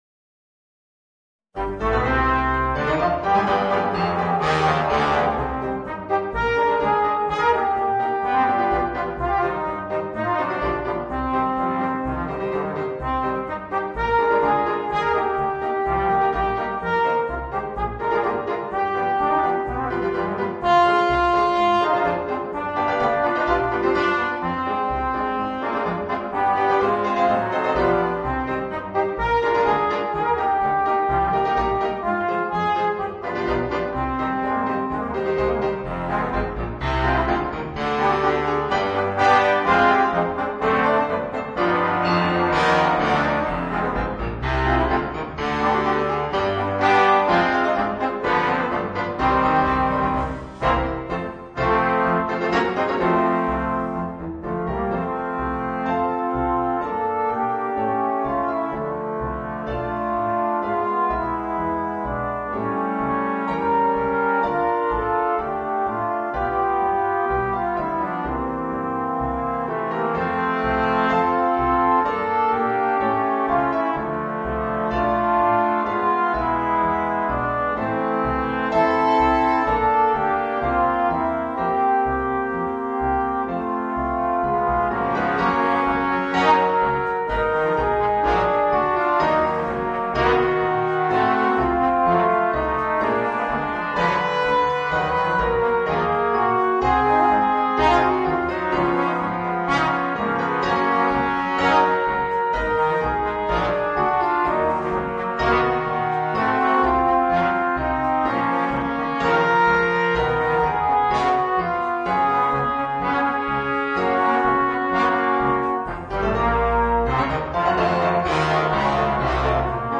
Voicing: 4 Trombones